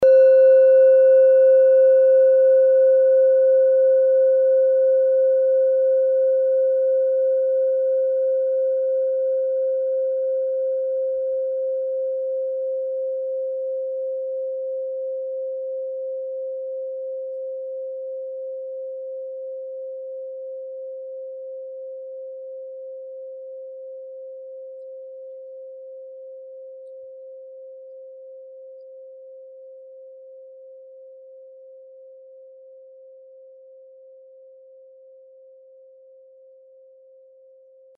Kleine Klangschale Nr.11
(Ermittelt mit dem Minifilzklöppel)
Meisterton:
kleine-klangschale-11.mp3